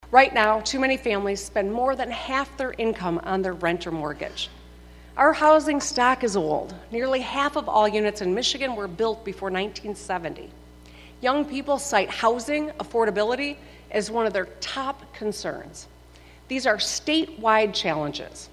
AUDIO: Governor Whitmer delivers State of the State